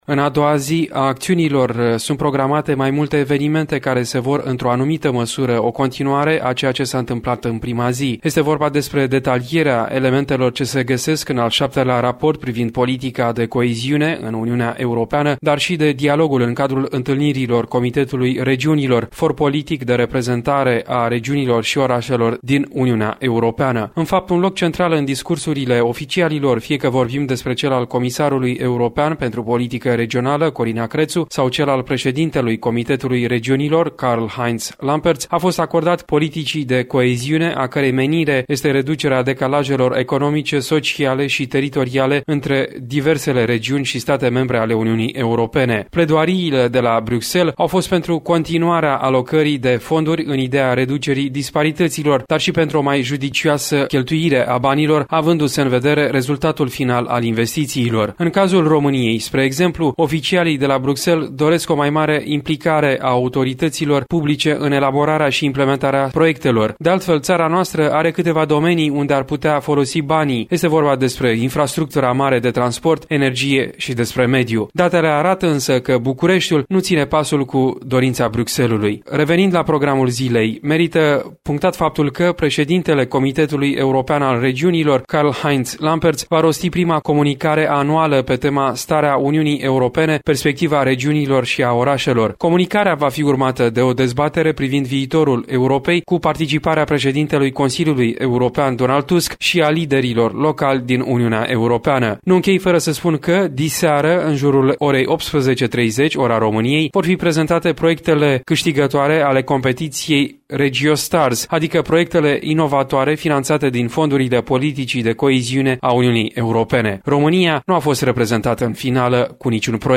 O relatare